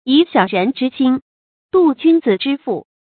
注音：ㄧˇ ㄒㄧㄠˇ ㄖㄣˊ ㄓㄧ ㄒㄧㄣ ，ㄉㄨㄛˊ ㄐㄩㄣ ㄗㄧˇ ㄓㄧ ㄈㄨˋ
讀音讀法：